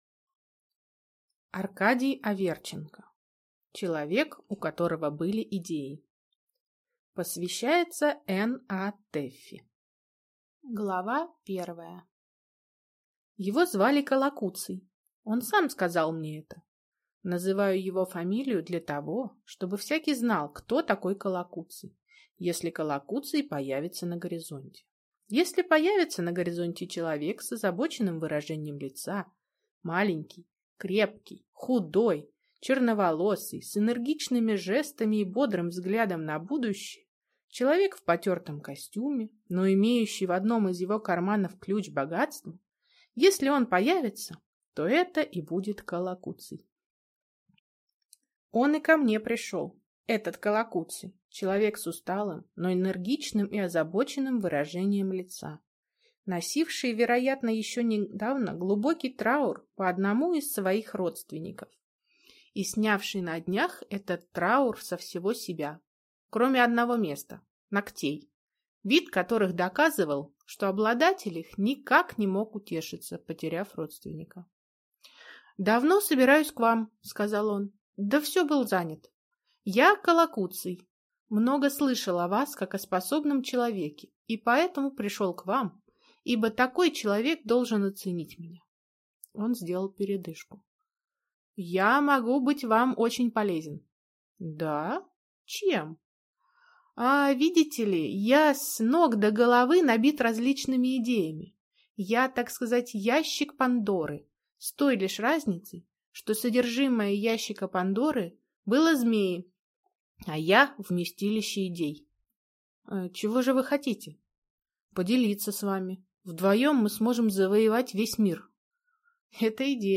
Аудиокнига Человек, у которого были идеи | Библиотека аудиокниг